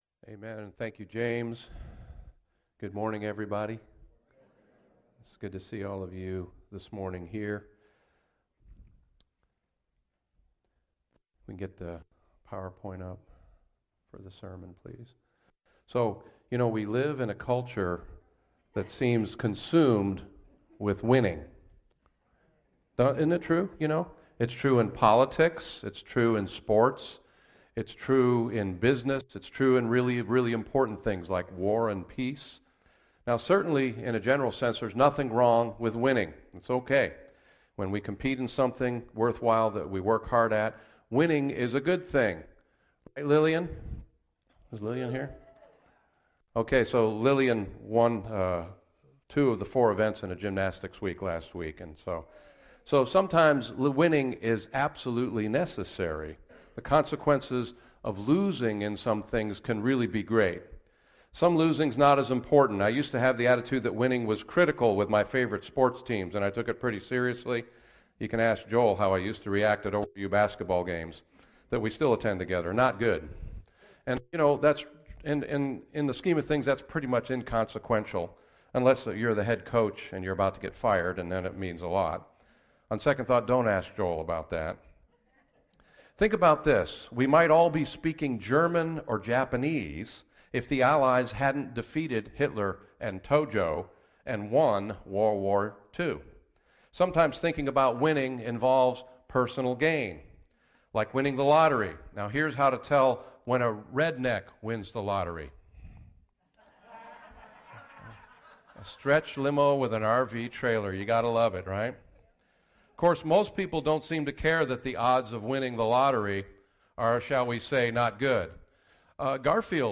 Tulsa Christian Fellowship Sermon Audio